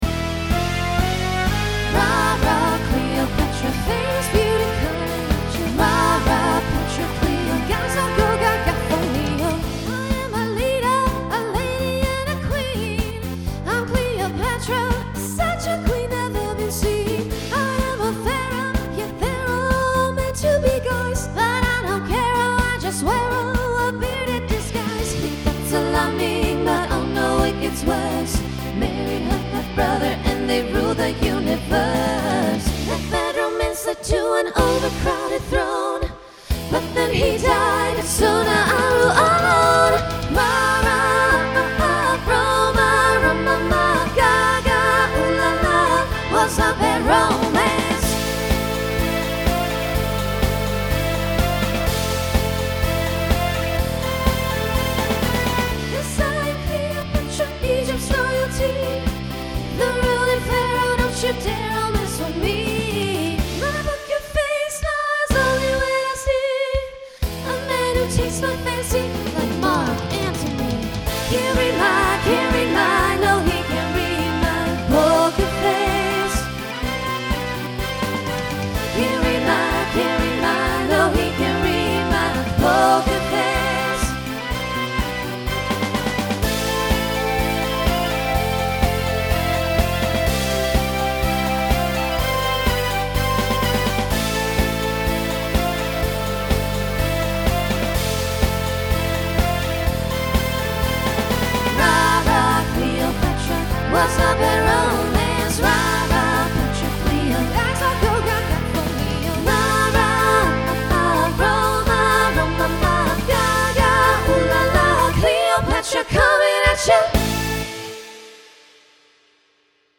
New SATB voicing for 2023.